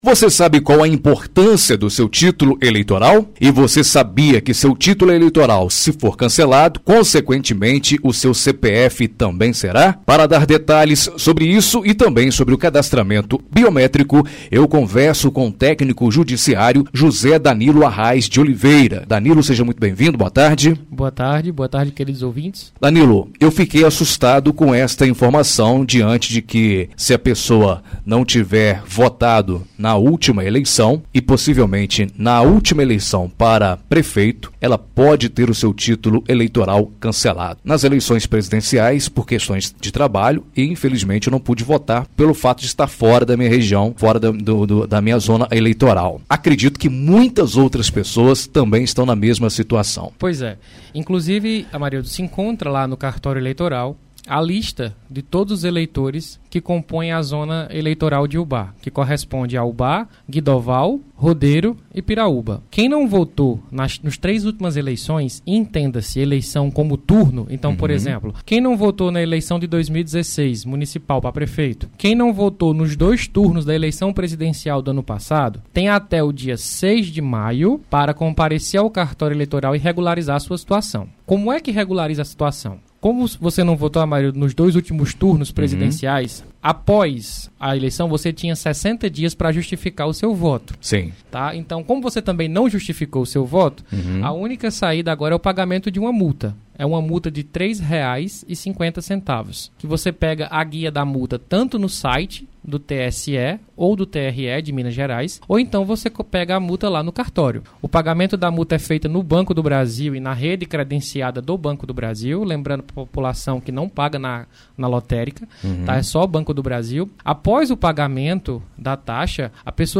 Entrevista exibida na Rádio Educadora AM/FM